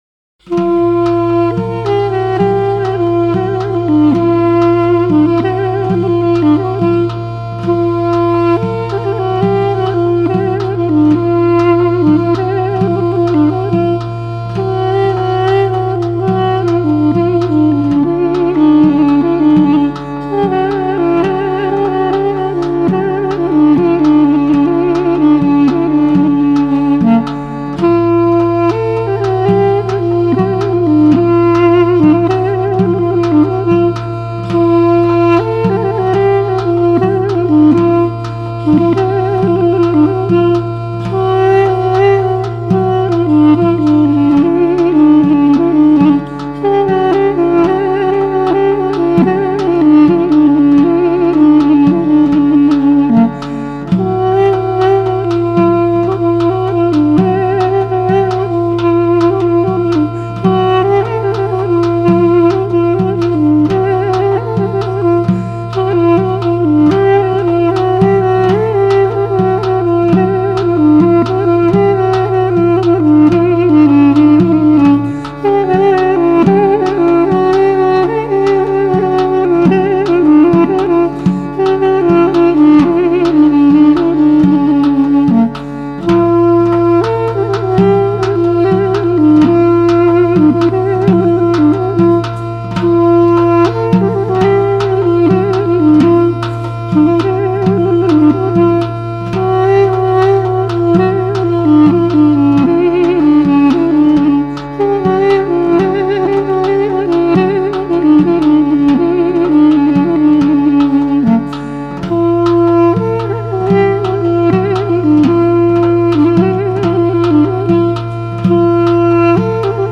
The warm, nasal tones of the duduk played by